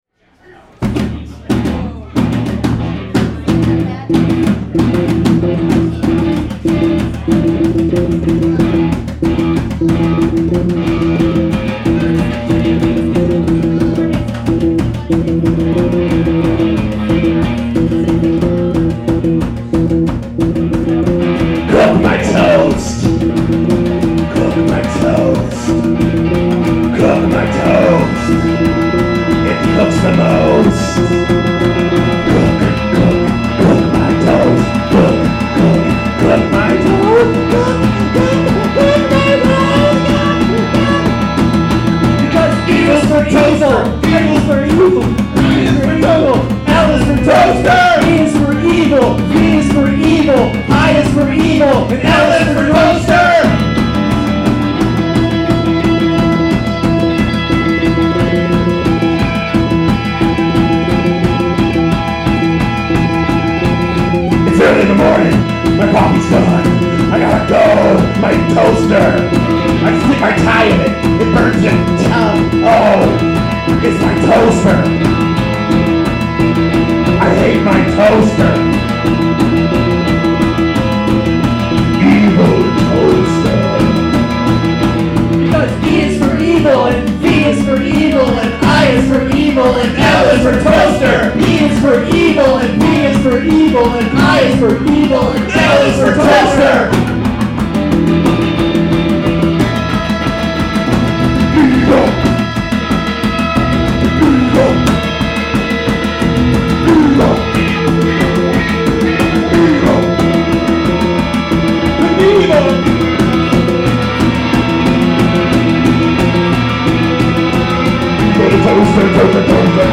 100% Improvised Live Songs